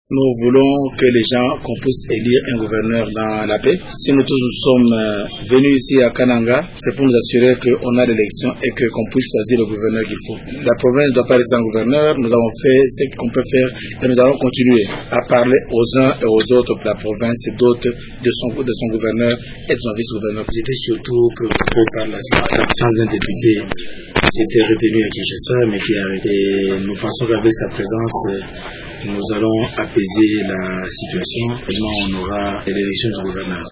Extrait des propos de Ngoy Mulunda